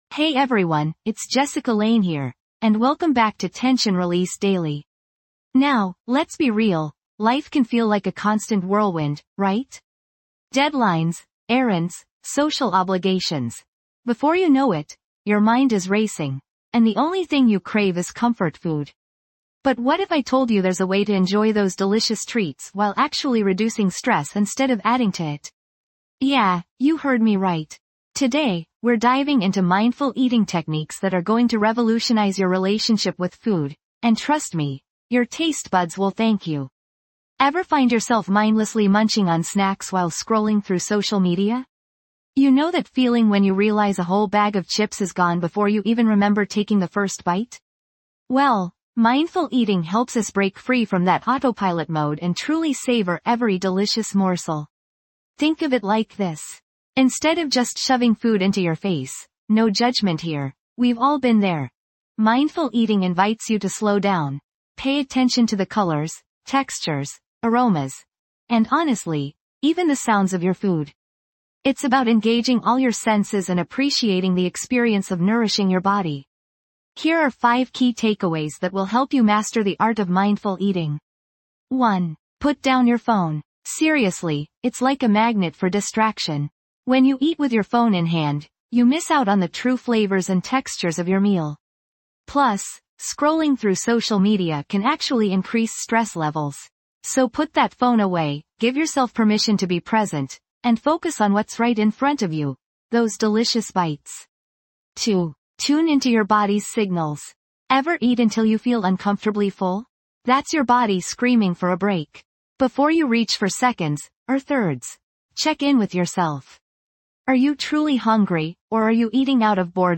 Through guided meditations, soothing soundscapes, and practical mindfulness techniques, we help you melt away stress, release tension from your body, and cultivate a sense of inner peace.